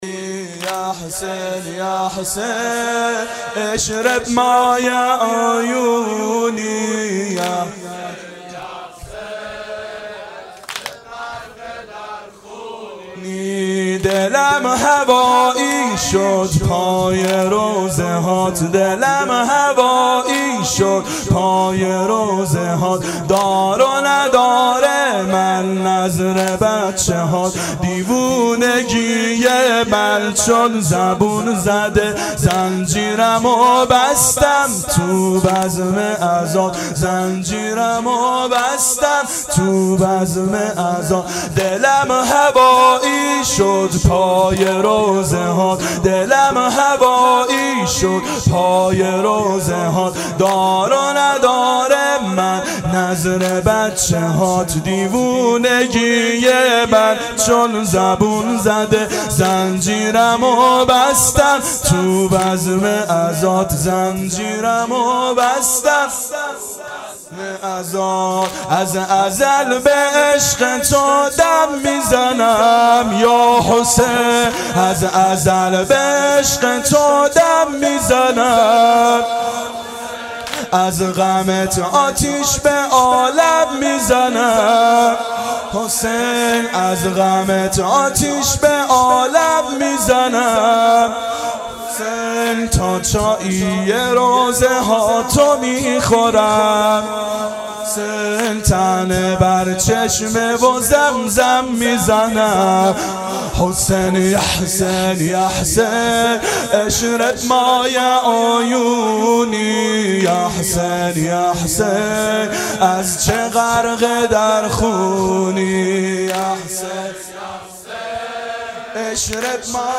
• دهه اول صفر سال 1390 هیئت شیفتگان حضرت رقیه س شب دوم (شب شهادت)